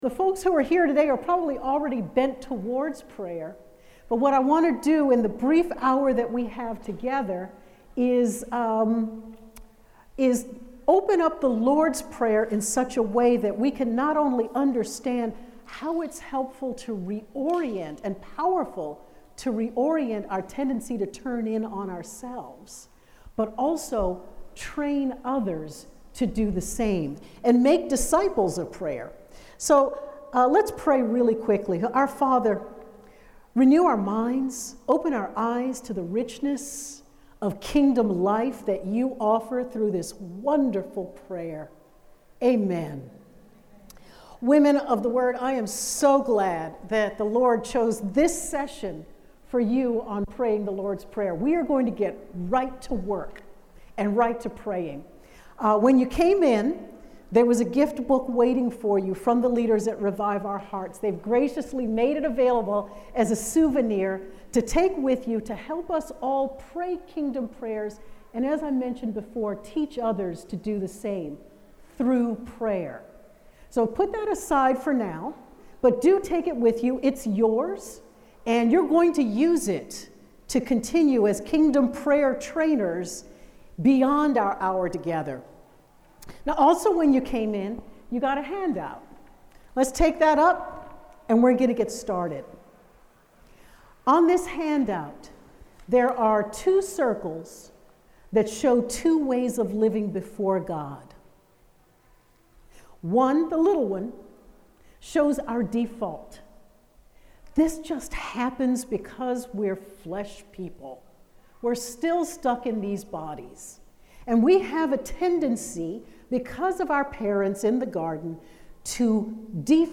Praying through the Lord’s Prayer, Part 1 | True Woman '25 | Events | Revive Our Hearts